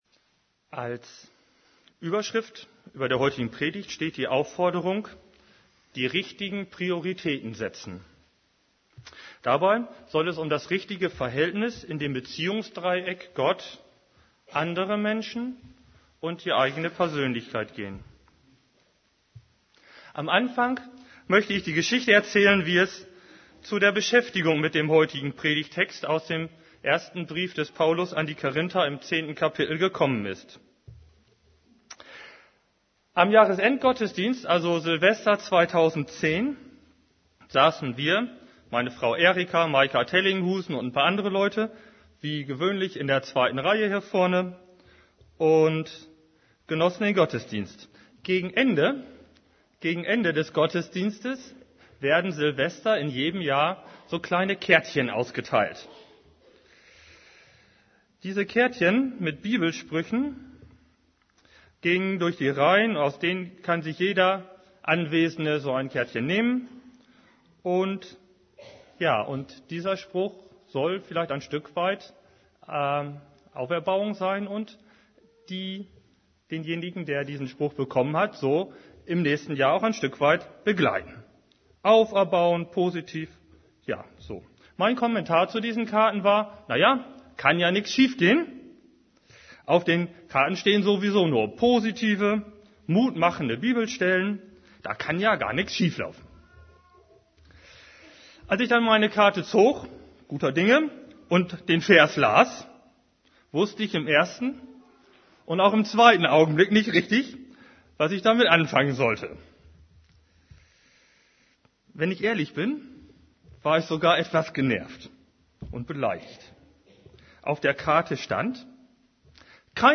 > Übersicht Predigten Die richtigen Prioritäten setzen Predigt vom 20.